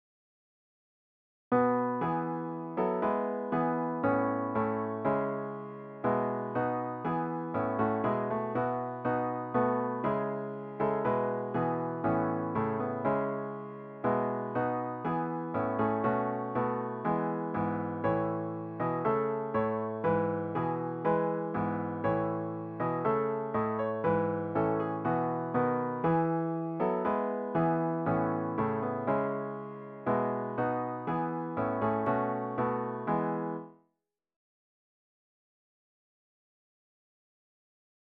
SATB Hymn